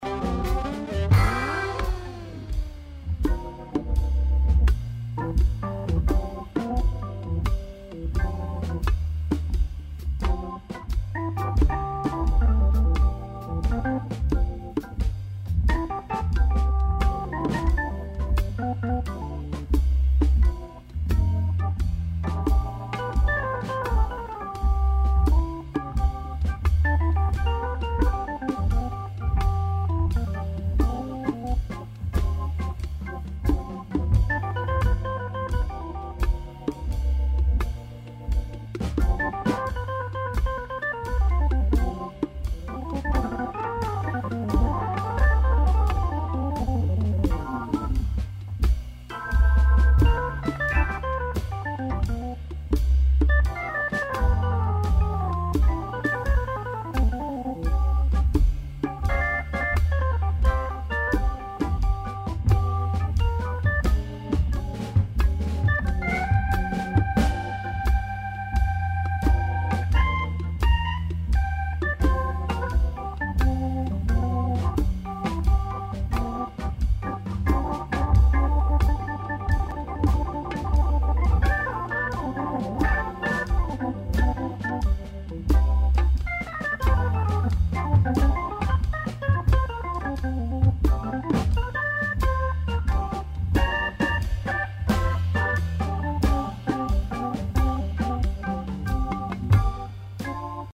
Attention, il y a 2 orgues sur ce coup.